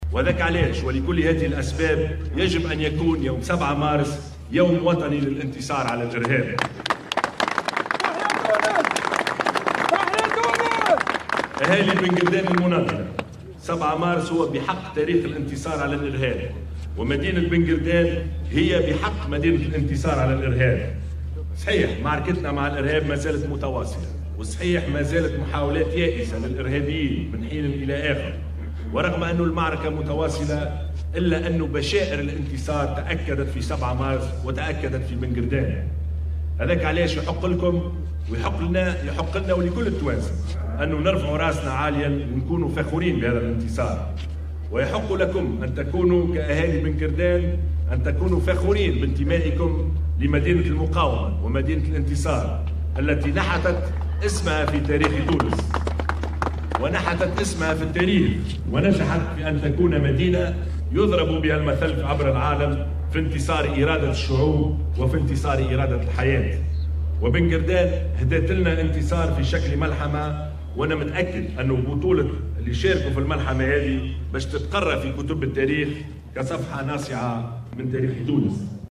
Le chef du gouvernement Youssef Chahed s'est déplacé ce mercredi à Ben Guerdane pour présider la commémoration du deuxième anniversaire de la bataille de Ben Guerdane.